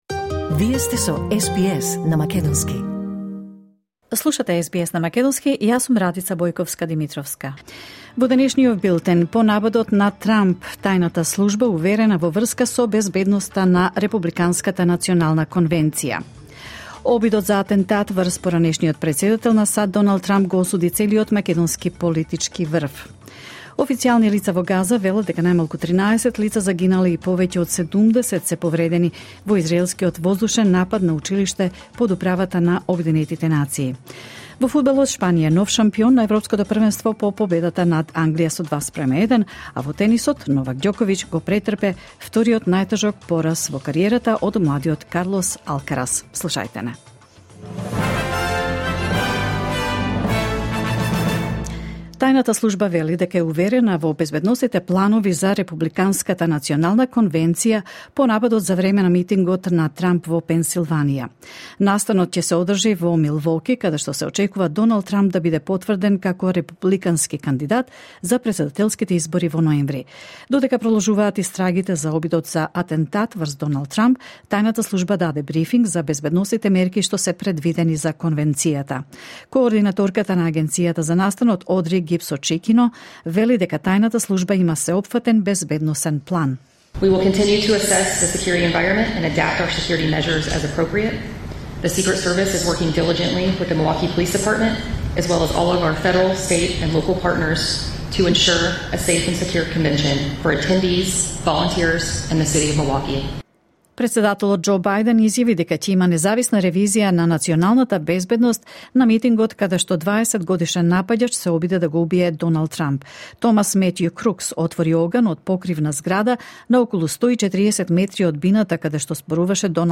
SBS News in Macedonian 15 July 2024